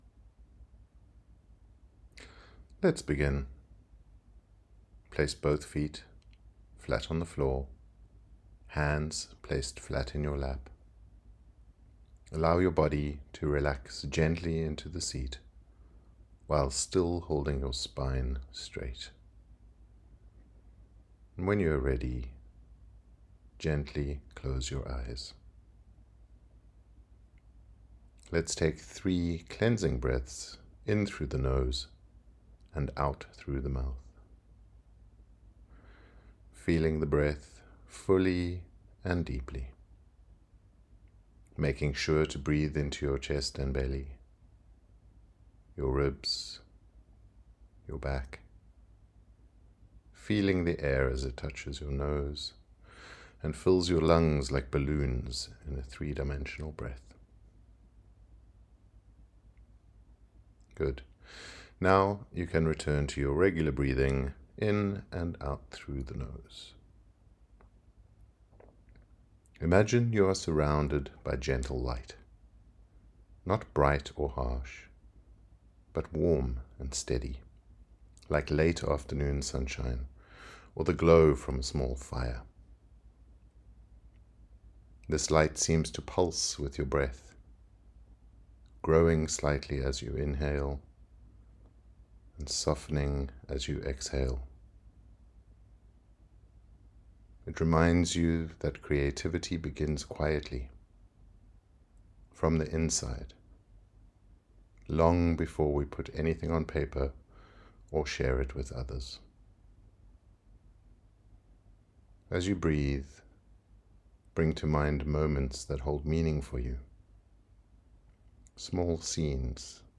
sTories In squaRes Meditation